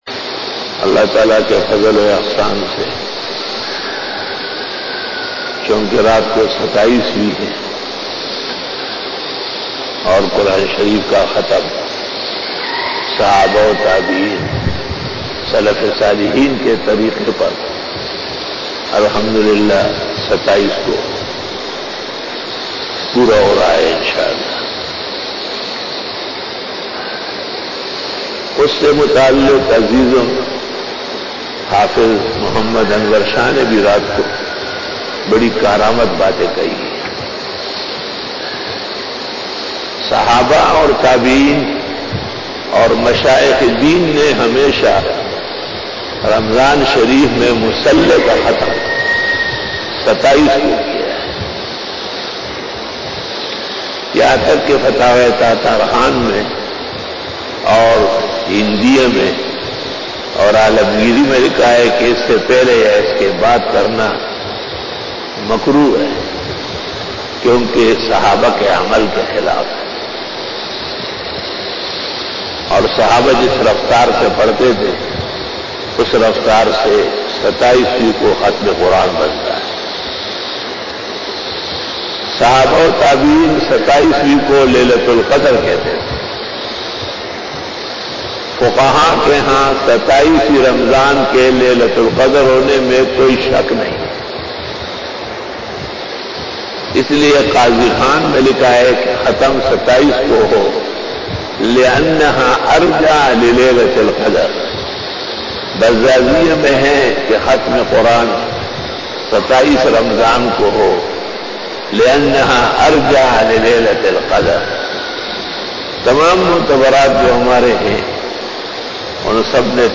After Fajar Byan
بیان بعد نماز فجر بروز منگل